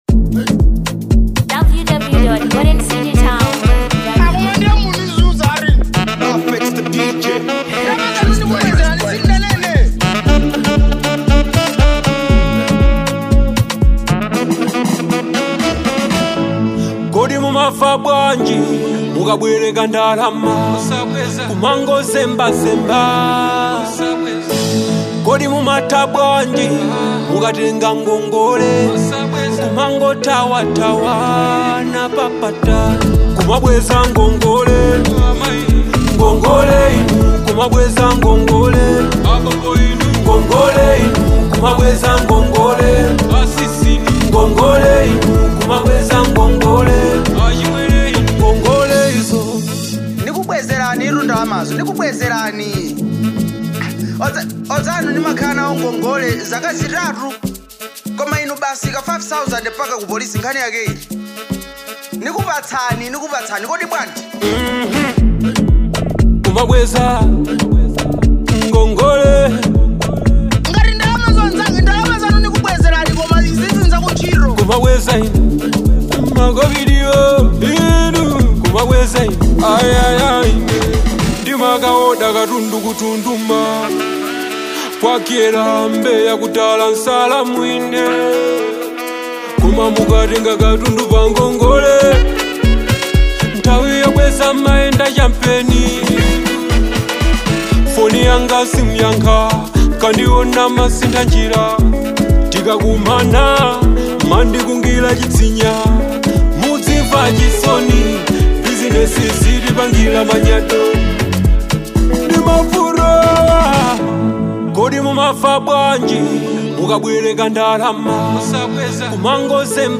2. Amapiano